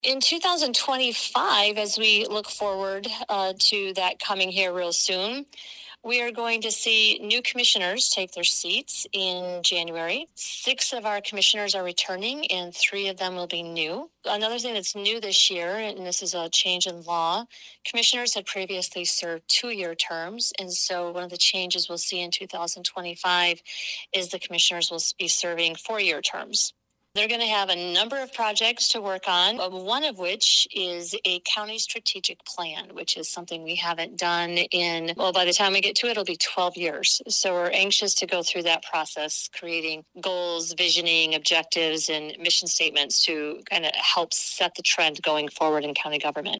County Administrator Kim Murphy highlighted the many accomplishments from the past year, and looked forward to 2025. Murphy spoke with WLEN News after the legislative dinner…